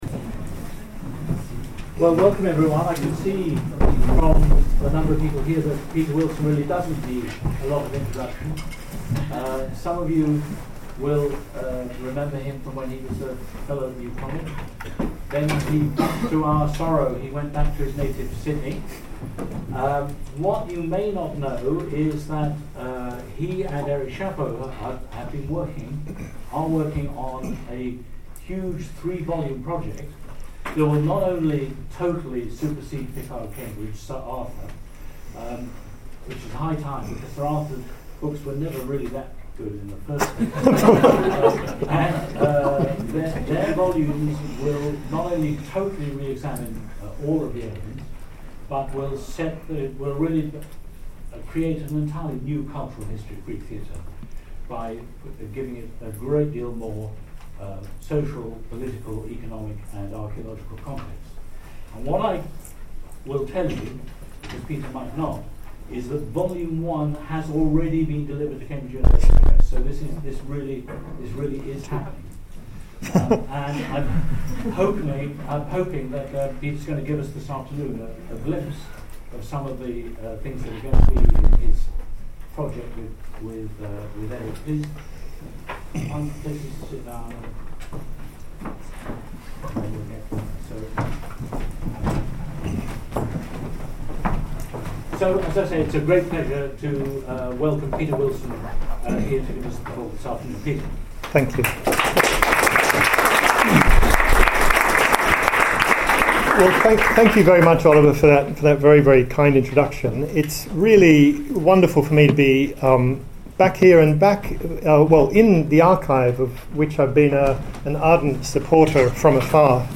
APGRD public lectures